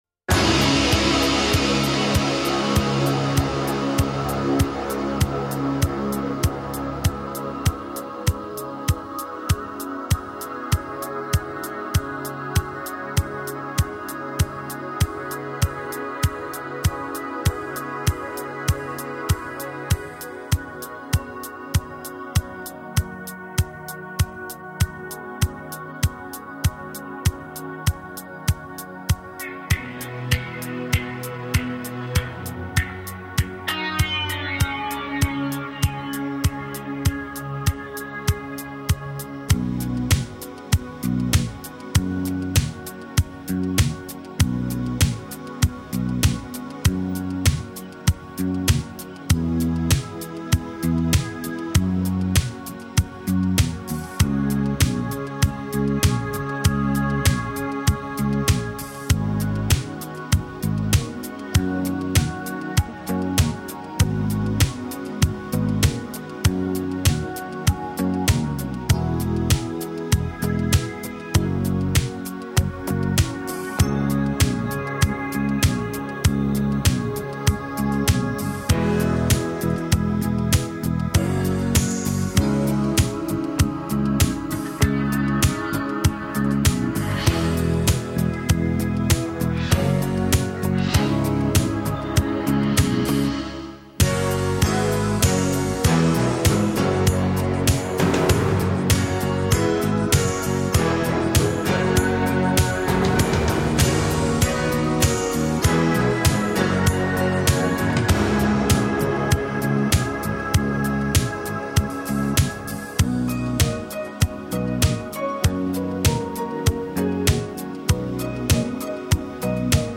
The instrumental version